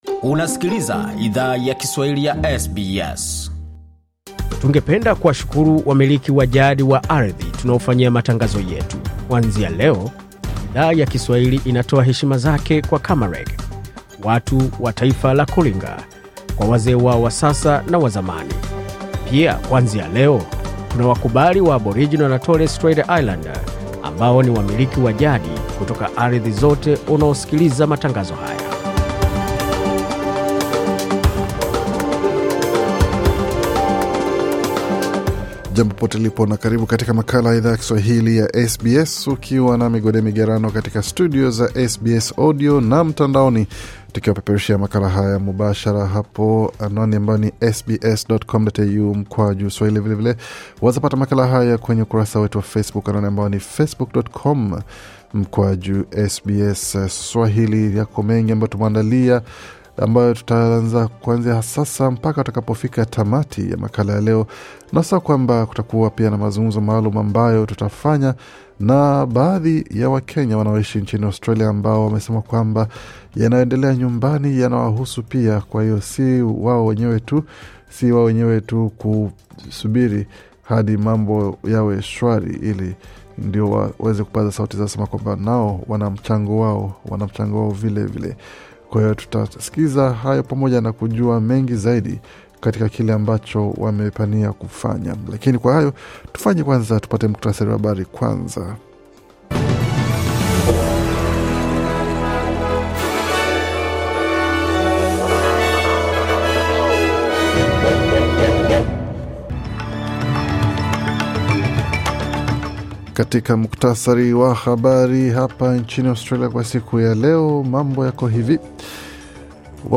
Taarifa ya habari 25 Juni 2024